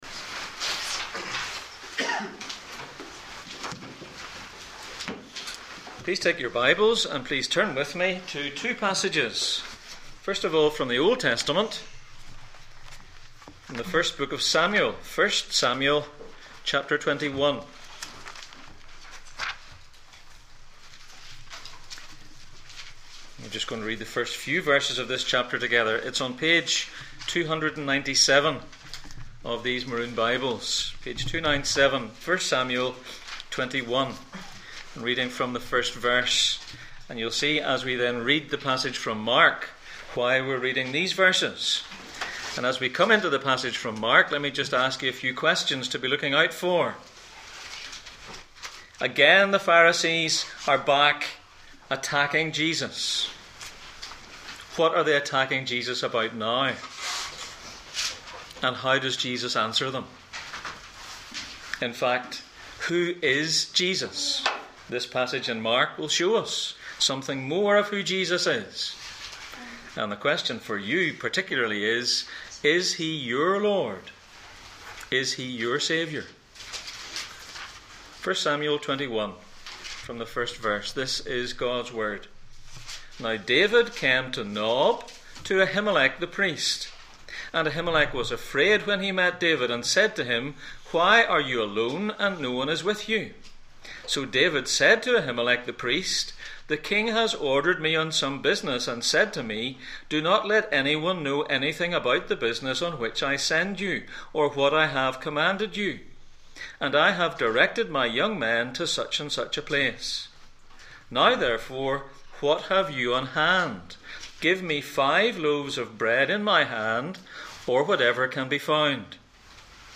Jesus in Mark Passage: Mark 2:23-28, Deuteronomy 23:24-25, 1 Samuel 21:1-6, Numbers 15:32-36, Isaiah 58:13-14 Service Type: Sunday Morning